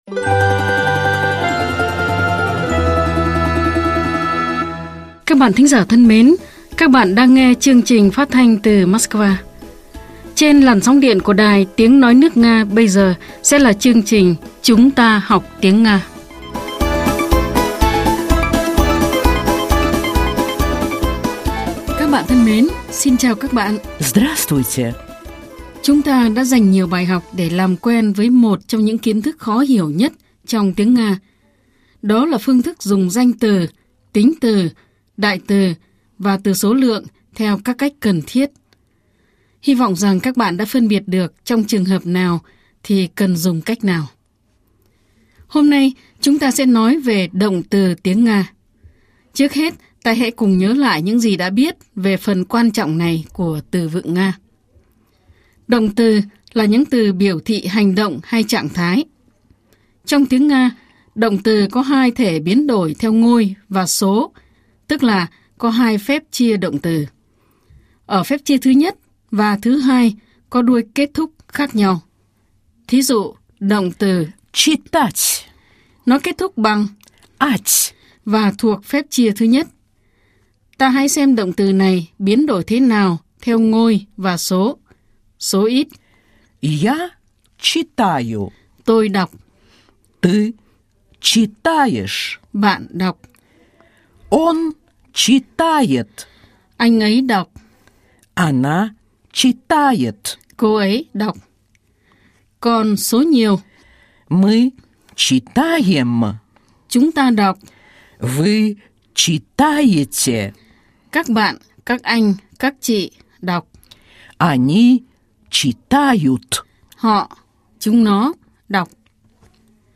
Chưa có phản hồi 0 lượt thích Học tiếng Nga qua bài giảng
Nguồn: Chuyên mục “Chúng ta học tiếng Nga” đài phát thanh  Sputnik